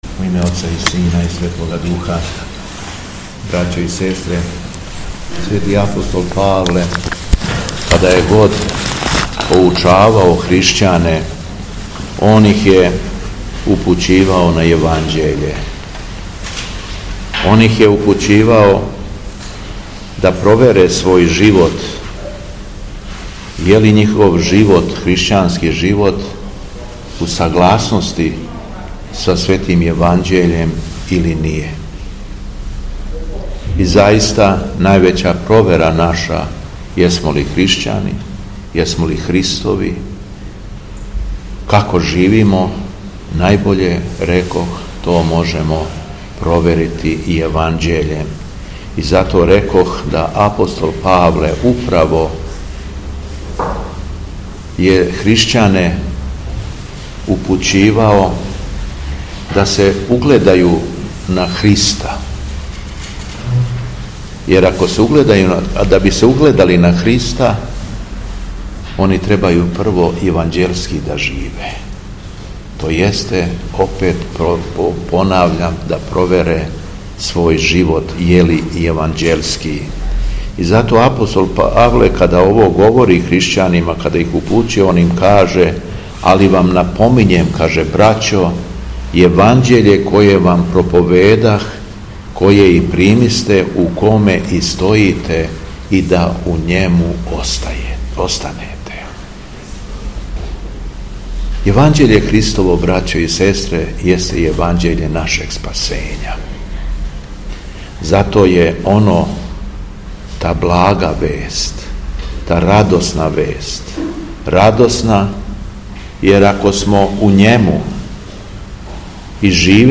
Беседа Његовог Високопреосвештенства Митрополита шумадијског г. Јована
После прочитаног Јеванђеља Високопреосвећени владика се обратио верном народу беседом:
У уторак, 18. фебруара 2025. године, када се наша Света Црква молитвено сећа свете мученице Агатије и светог Полиевкта, Његово Високопреосвештенство Митрополит шумадијски г. Јован служио је Свету Архијерејску Литургију у храму Светог великомученика Димитрија у крагујевачком насељу Сушица уз саслужење братства овога светога храма.